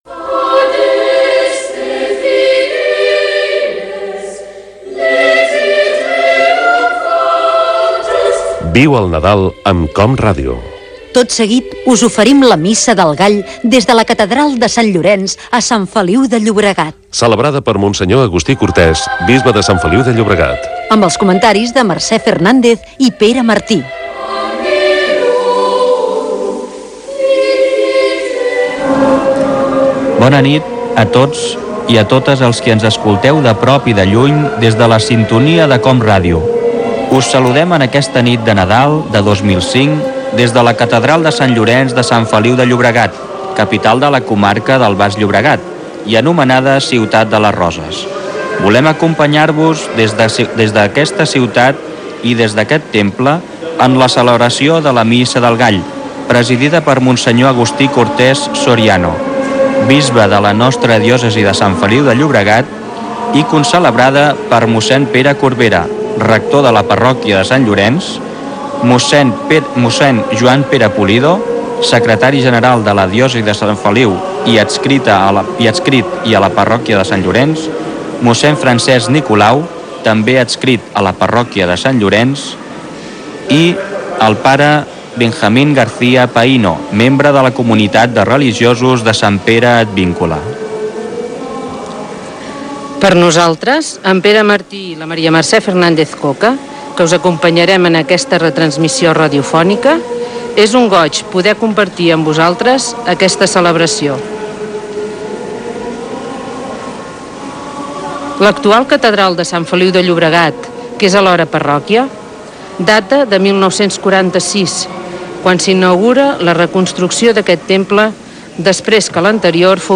Inici de la transmissió des de la Catedral de Sant Llorenç a Sant Feliu de Llobregat ,celebrada pel Bisbe Agustí Cortés.
Careta del programa, presentació i inici de la missa.